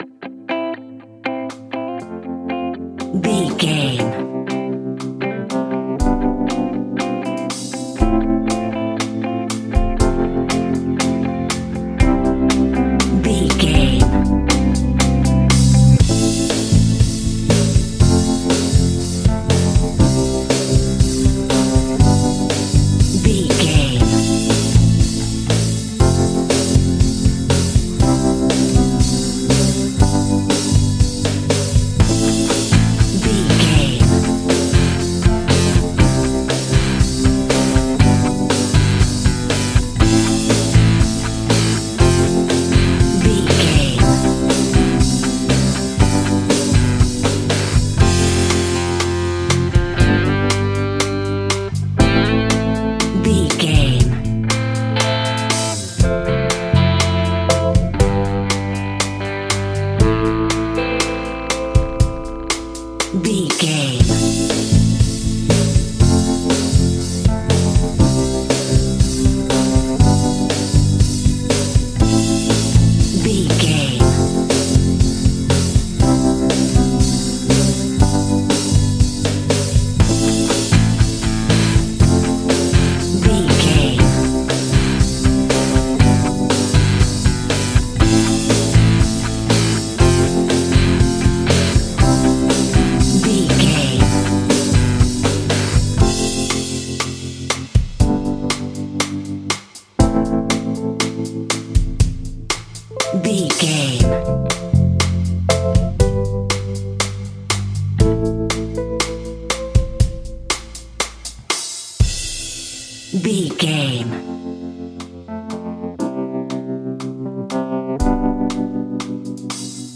Ionian/Major
fun
energetic
uplifting
motivational
indie pop rock instrumentals
guitars
bass
drums
piano
organ